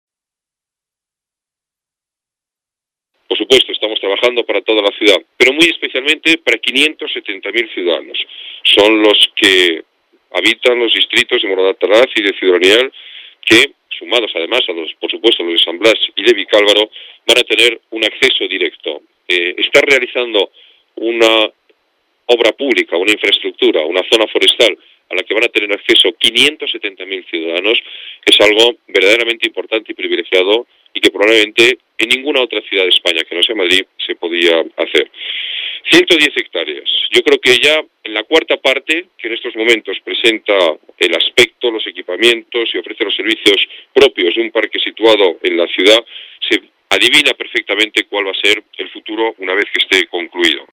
Nueva ventana:El alcalde informa de los datos del Parque de la Cuña Verde